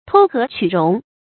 偷合取容 注音： ㄊㄡ ㄏㄜˊ ㄑㄩˇ ㄖㄨㄙˊ 讀音讀法： 意思解釋： 奉承迎合別人，使自己能茍且地生活下去。